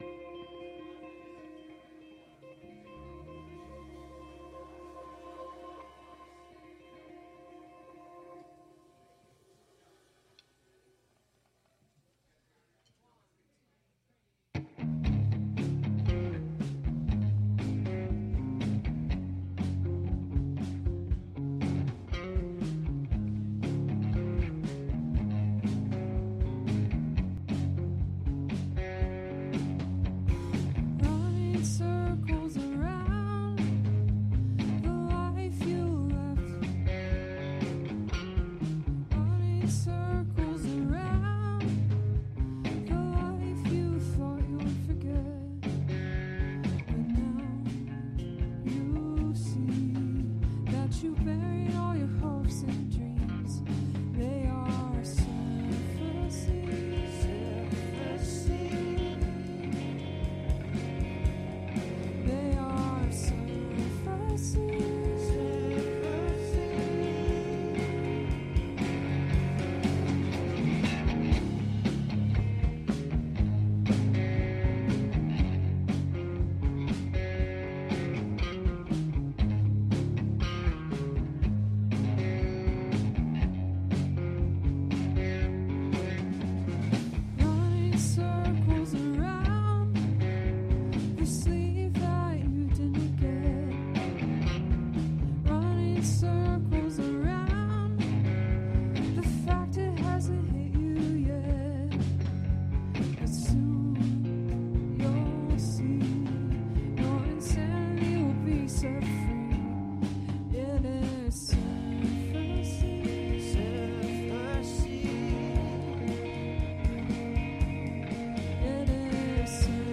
Live from The Getaway River Bar